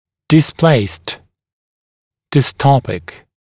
[dɪs’pleɪst] [dɪs’tɔpɪk][дис’плэйст] [дис’топик]дистопированный